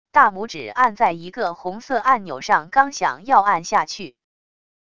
大拇指按在一个红色按钮上刚想要按下去wav音频